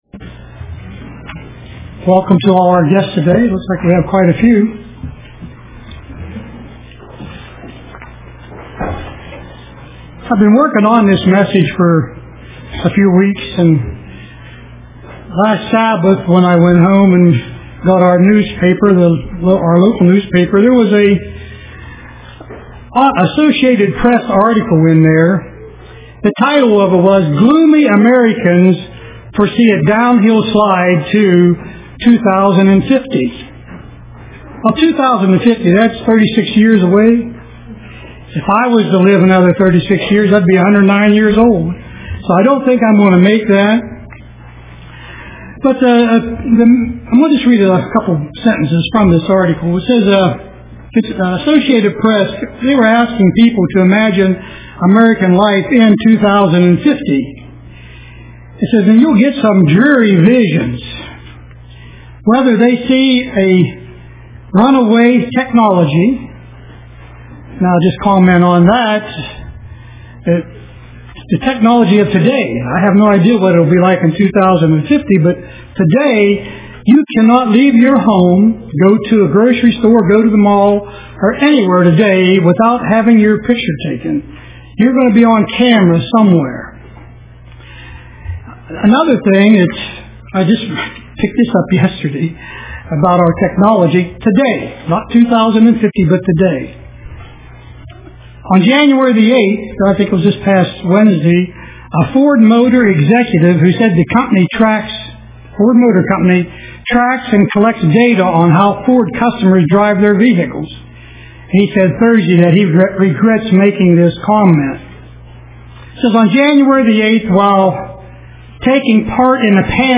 Print The Battle is the Lord's UCG Sermon Studying the bible?